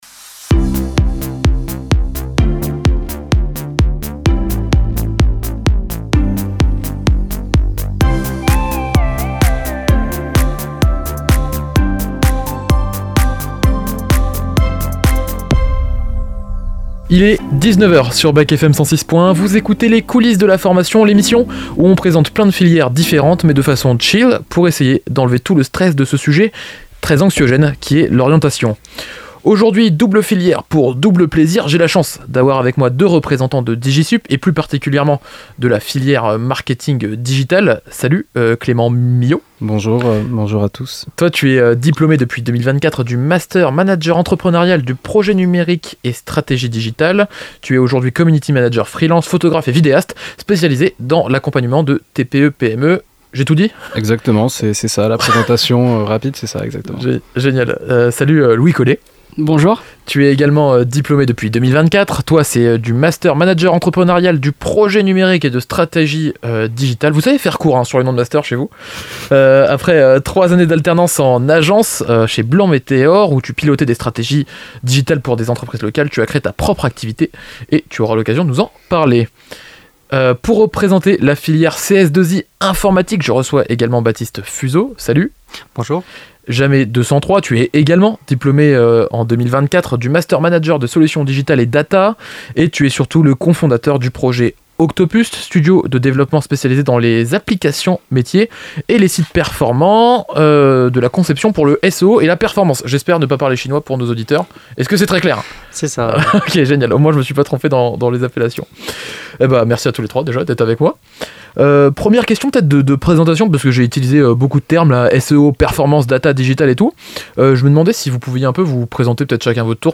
Le podcast de l'émission du jeudi 26 mars 2026